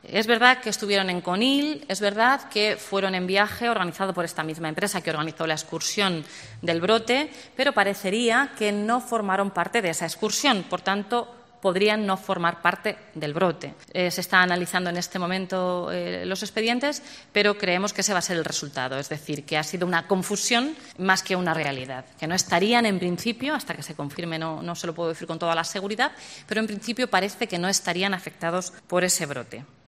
Declaraciones de la portavoz de Castilla-La Mancha